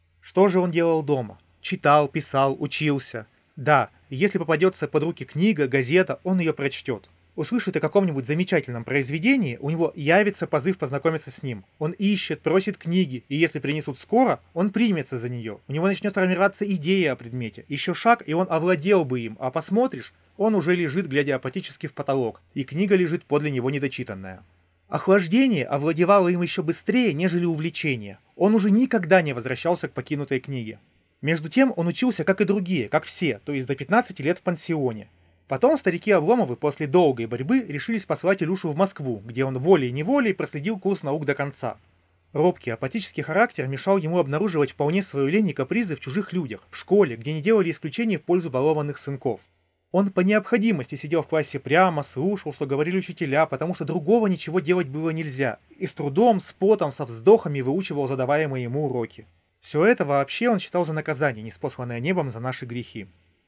Для начала активируем классическую кардиоидную диаграмму направленности и просто зачитаем небольшой кусочек текста, расположив голову говорящего по нормали к передней поверхности микрофона на расстоянии около 30 см.
Пример записи на микрофон (кардиоидная диаграмма, говорящий в 30 см)
Голос звучит разборчиво и полновесно, все интонации переданы отлично — микрофон сразу показал себя с лучшей стороны. Возможно, чуть резковато подаются сибилянты, но это буквально в пару кликов исправляется при обработке записи в любом редакторе.